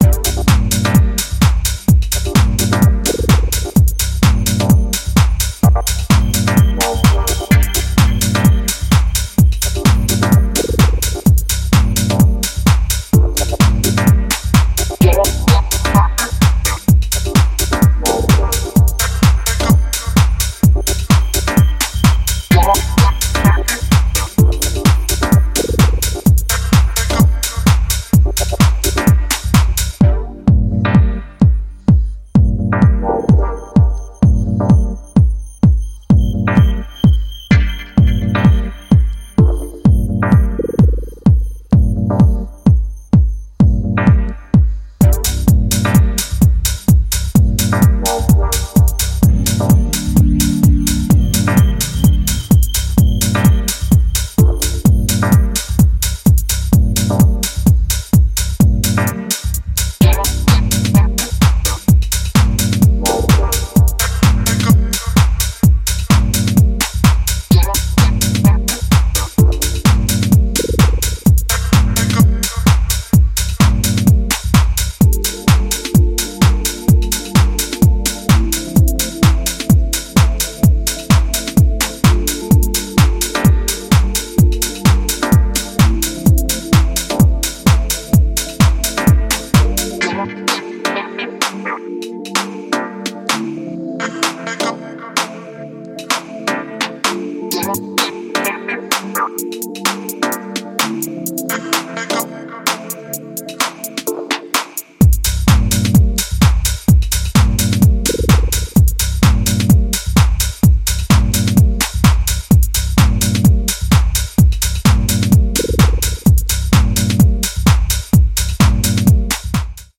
brings more physical drum work and infectious finger clicks
A useful, heartwarming EP of modern deep house music.